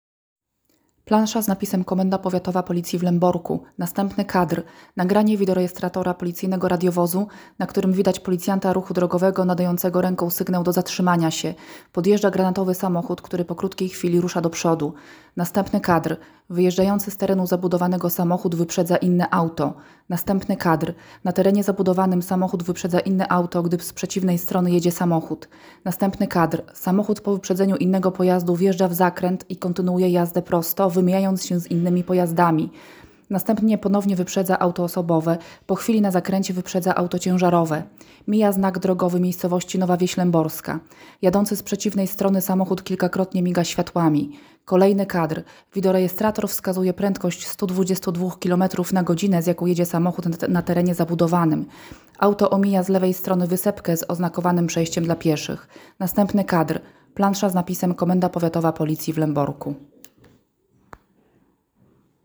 Nagranie audio audiodeskrypcja_materialu_filmowego.m4a